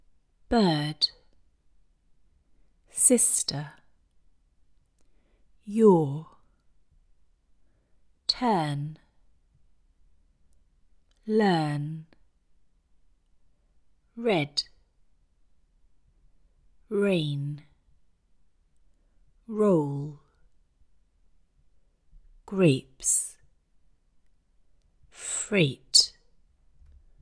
This can result in words like “no” being mispronounced with the single, Italian short vowel /no/ rather than with the elongated diphthong with two positions, as it is articulated in the RP pronunciation: /nəʊ/.
Received Pronunciation is non-rhotic, meaning that this type of accent sometimes has a silent r, such as in the words car or farm.